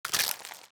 Crunch Bite Item (4).wav